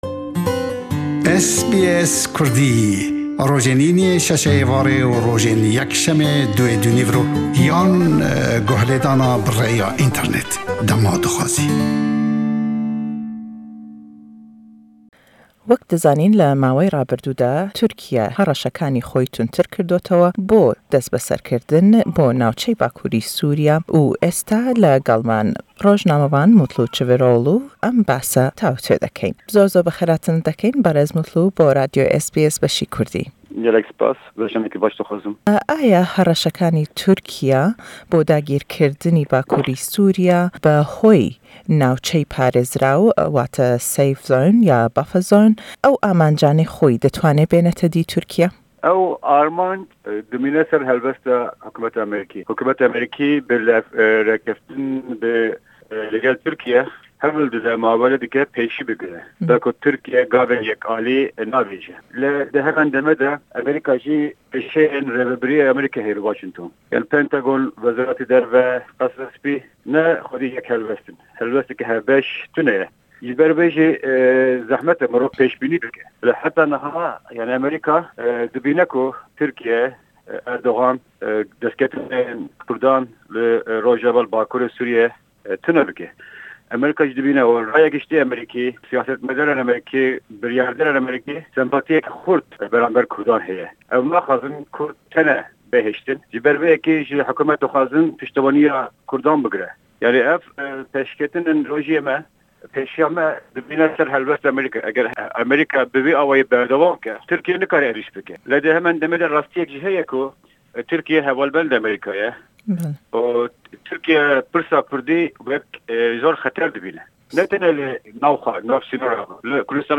Le em hevpeyvîne